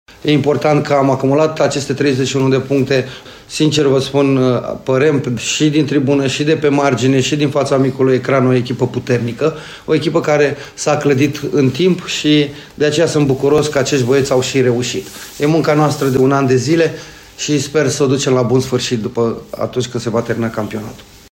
Antrenorul cărășenilor, Flavius Stoican, despre zestrea de puncte acumulată după remiza de ieri, 0-0, în nocturnă, acasă, cu FC Argeș: